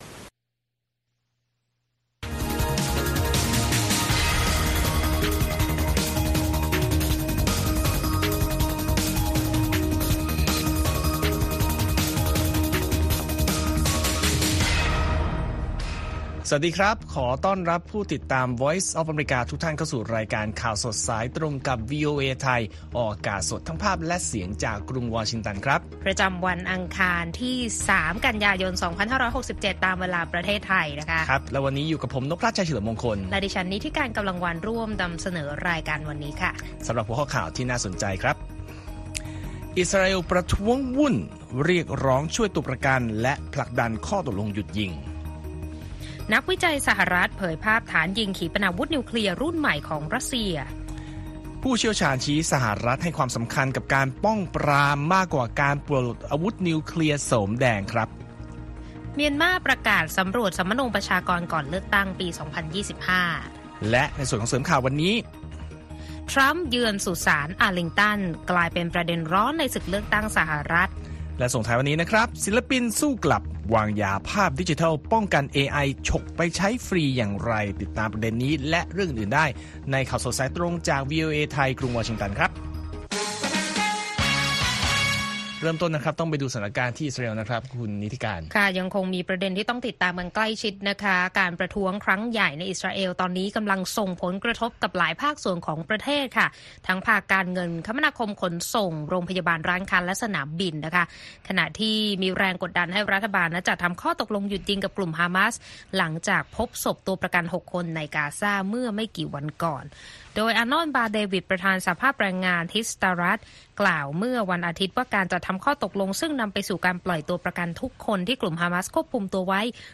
ข่าวสดสายตรงจากวีโอเอ ไทย วัน อังคาร ที่ 3 ก.ย. 2567